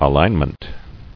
[a·lign·ment]